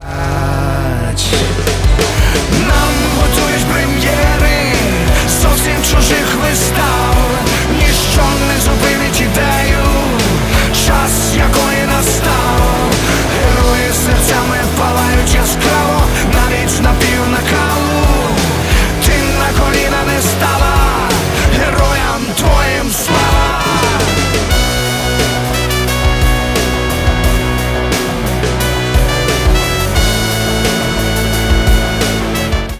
вдохновляющие
украинский рок
воодушевляющие